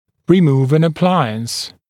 [rɪ’muːv ən ə’plaɪəns][ри’му:в эн э’плайэнс]выводить аппарат (о съемном аппарате)